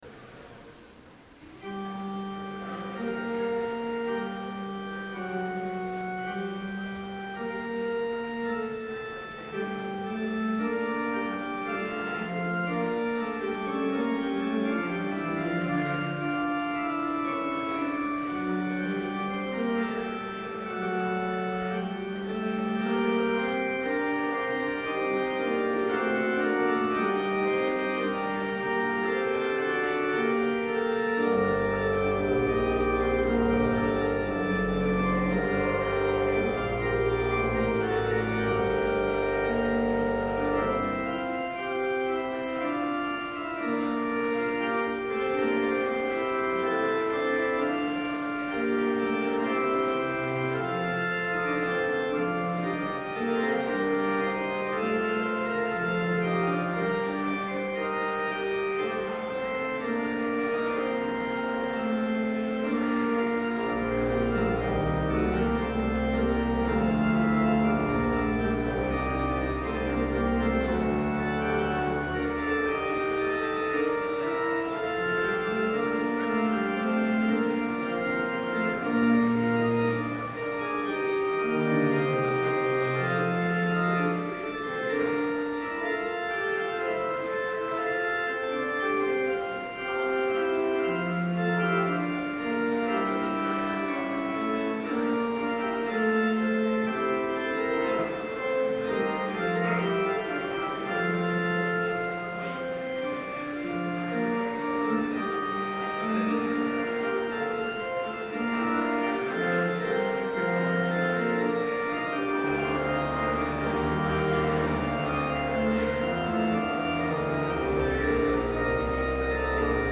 Complete service audio for Chapel - February 14, 2012